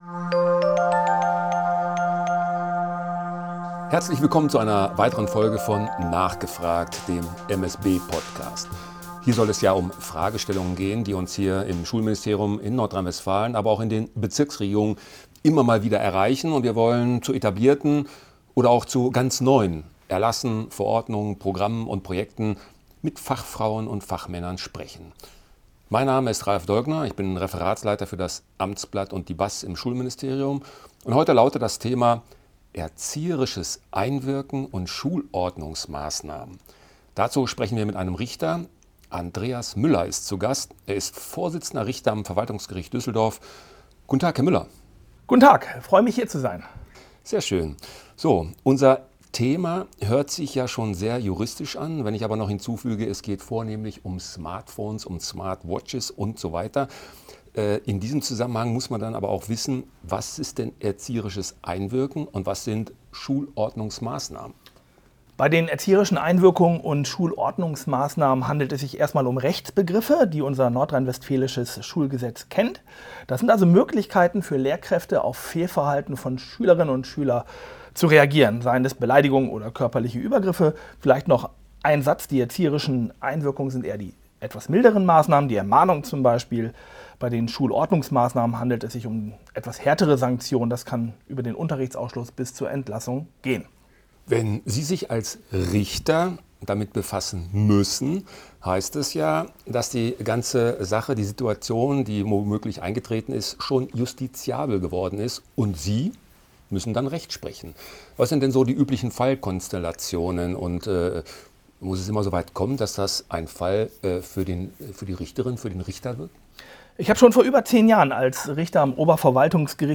Aufnahme vom 19. November 2024 im Ministerium für Schule und Bildung des Landes Nordrhein-Westfalen
Interviewpartner: Andreas Müller. Vorsitzender Richter am Verwaltungsgericht Düsseldorf